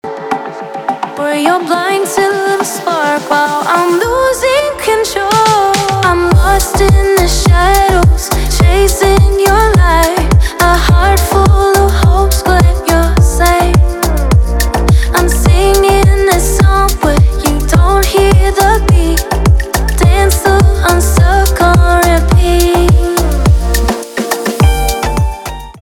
танцевальные
битовые , басы , нарастающие